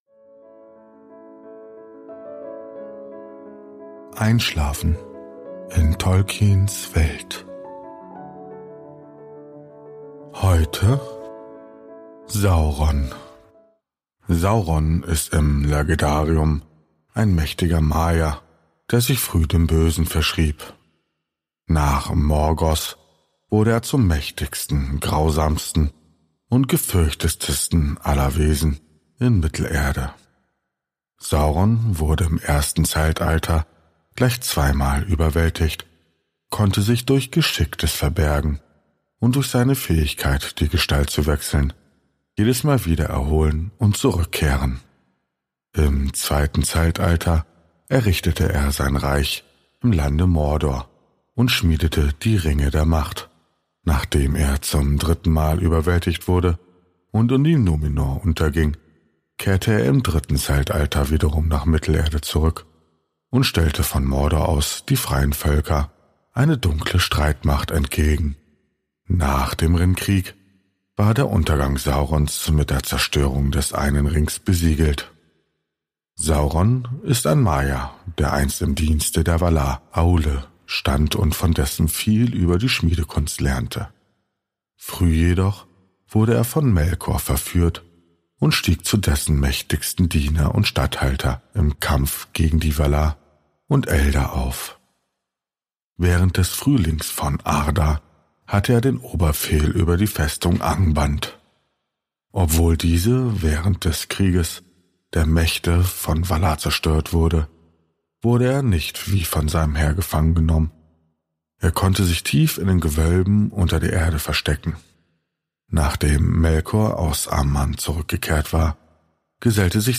Gutenachtgeschichten aus der Ardapedia